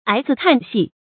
矮子看戲 注音： ㄞˇ ㄗㄧˇ ㄎㄢˋ ㄒㄧˋ 讀音讀法： 意思解釋： 比喻只知道附和別人，自己沒有主見。